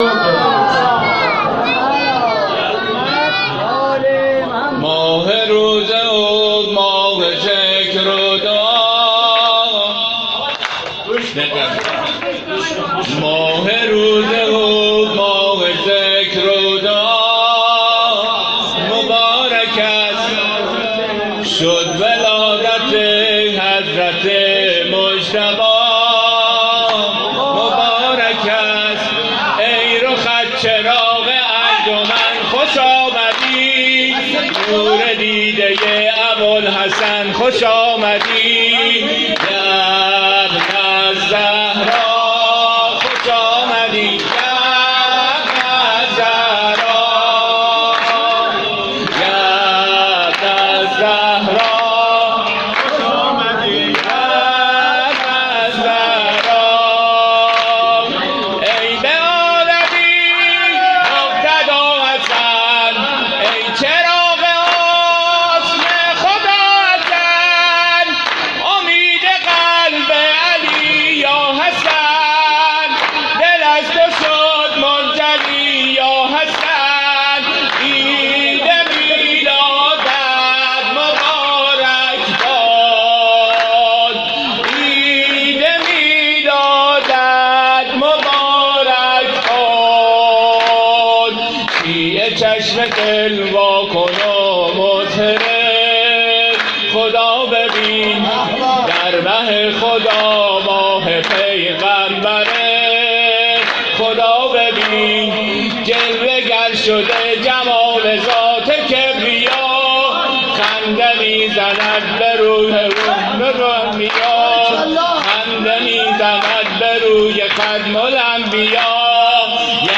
مولودی خوانی میلاد امام حسن مجتبی(ع)